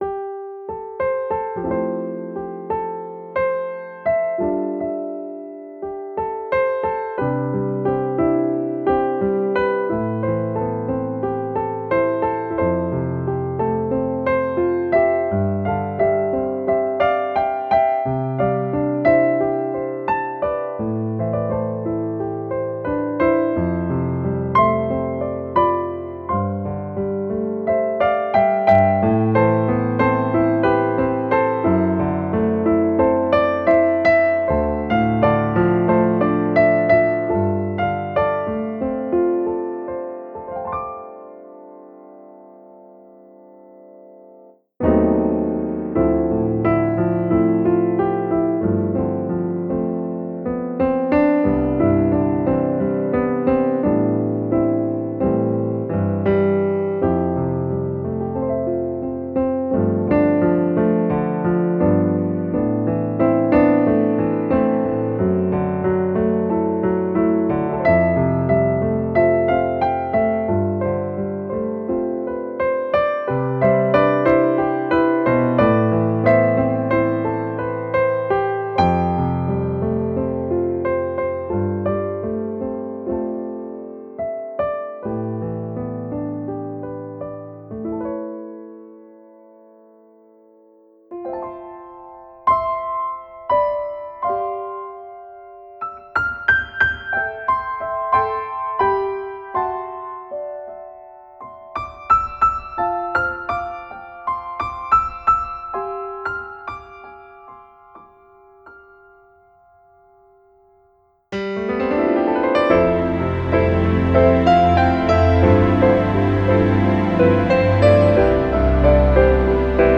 applause.ogg